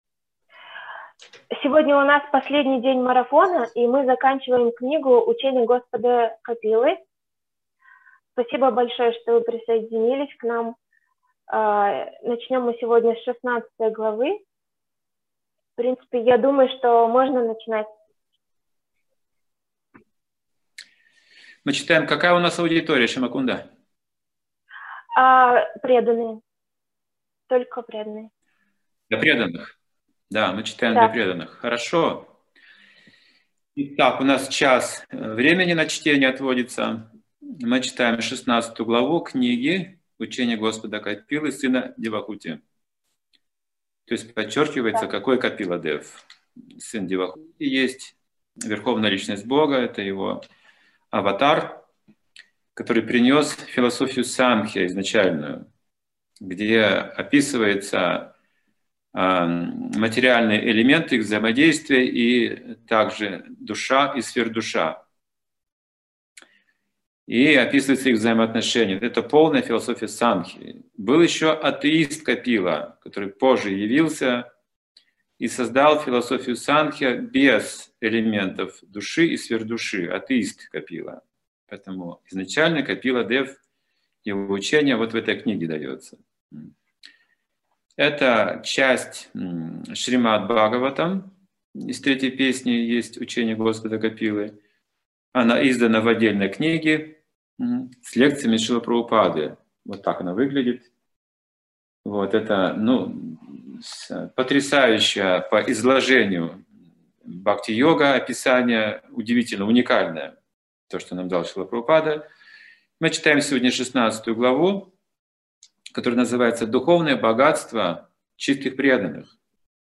Начало чтения главы и тема лекции